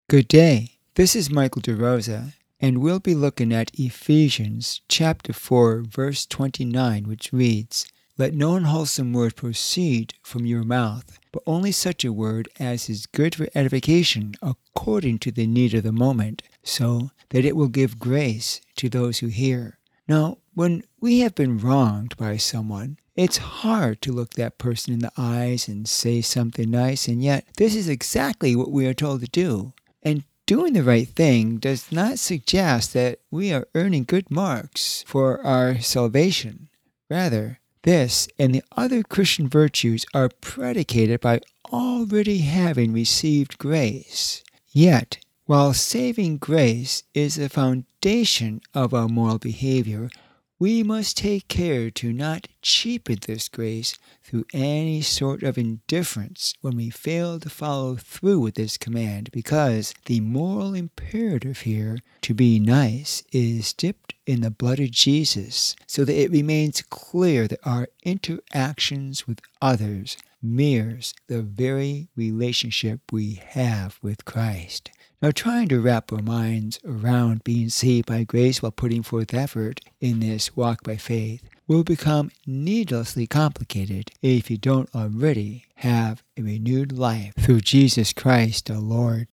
Radio Sermonettes